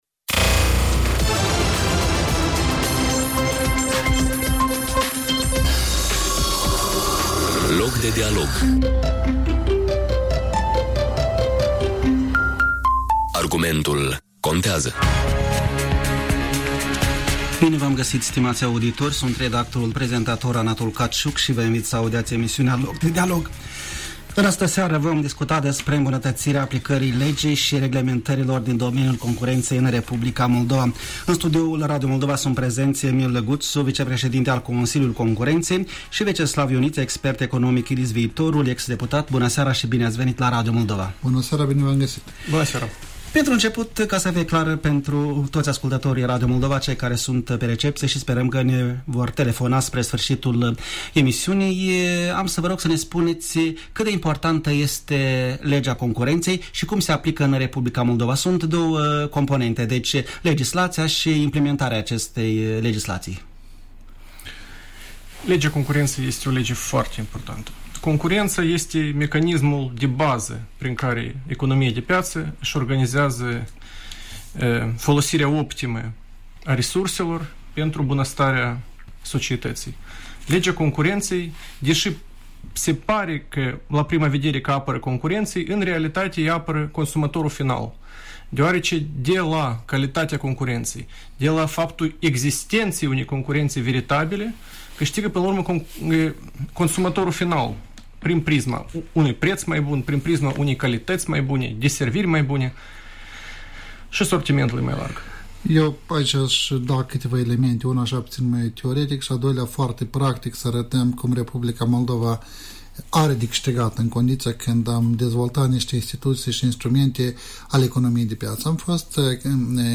Loc de dialog din 9 iunie 2017 | Interviuri | Consiliul Concurenţei
În studio: Emil Guţu, Vicepreşedinte al Consiliului Concurenţei